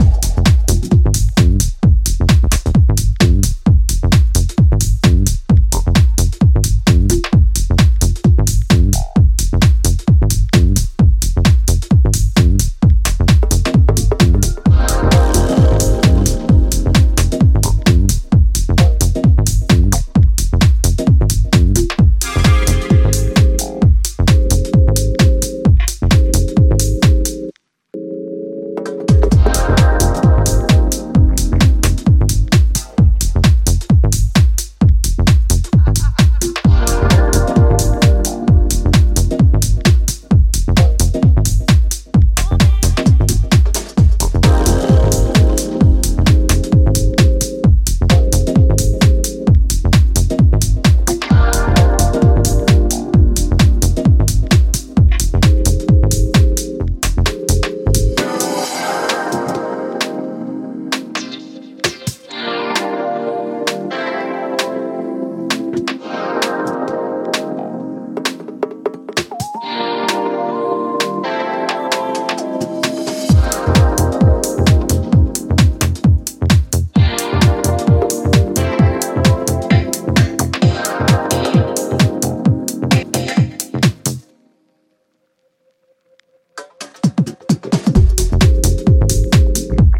ジャンル(スタイル) HOUSE / TECH HOUSE